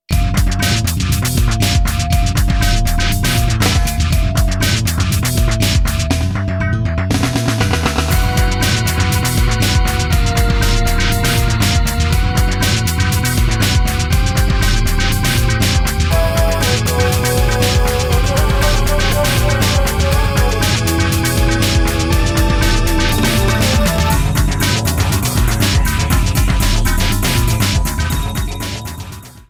trimmed to 29.5 seconds and faded out the last two seconds